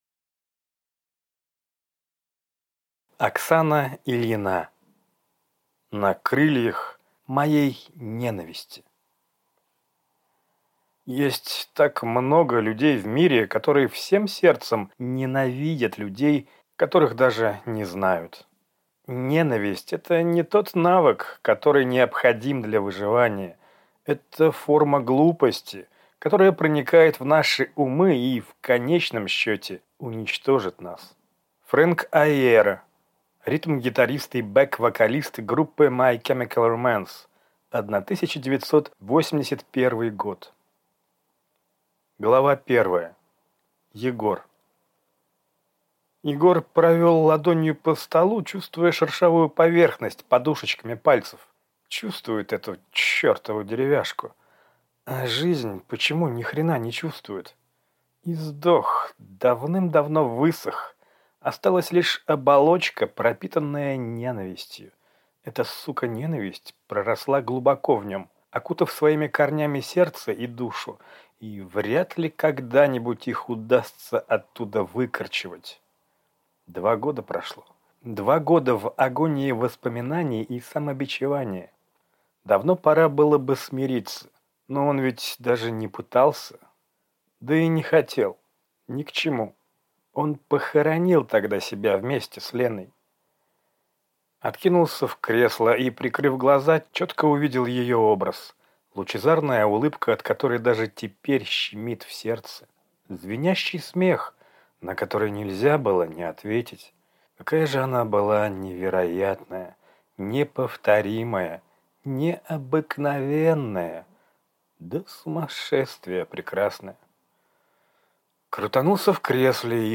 Аудиокнига На крыльях моей ненависти | Библиотека аудиокниг